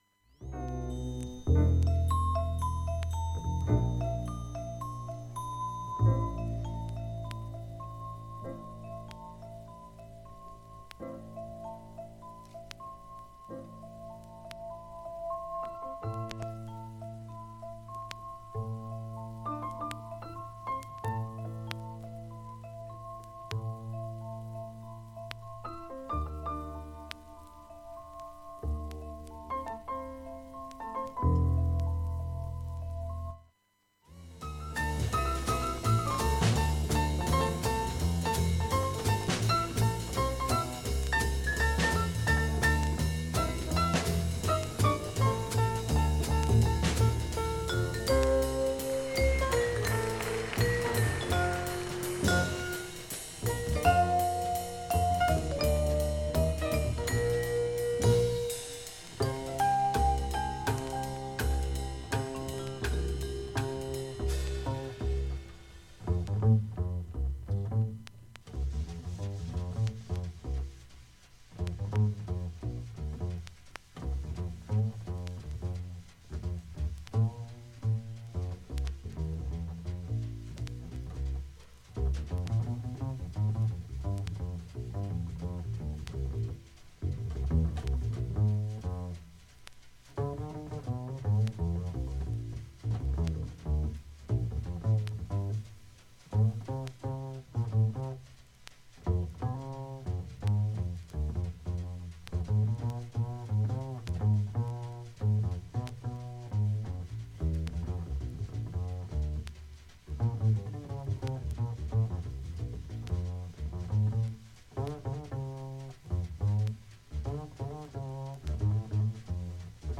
クリアな音質全曲試聴済み
５回までのかすかなプツが２箇所
3回までのかすかなプツが４箇所
単発のかすかなプツが４箇所
NYエイブリー・フィッシャー・ホールで